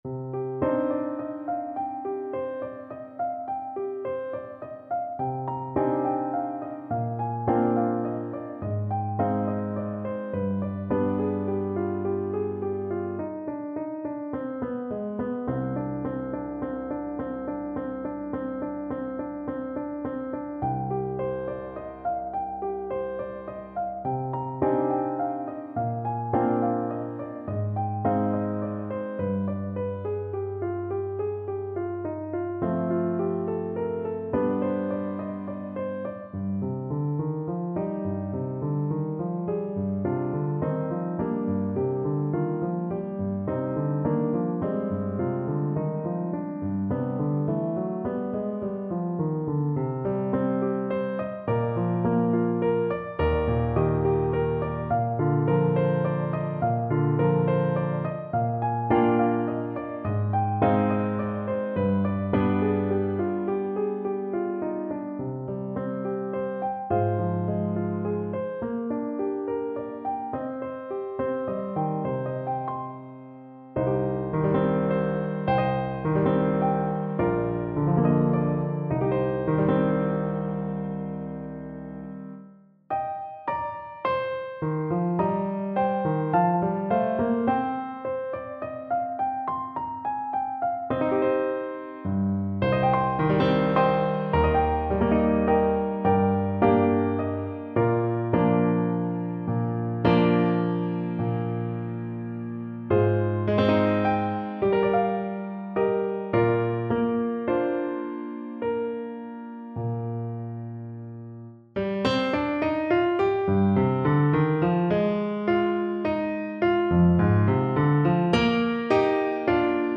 Alto Saxophone
A haunting arrangement of this famous British folk melody.
3/4 (View more 3/4 Music)
Fast, flowing =c.140
Traditional (View more Traditional Saxophone Music)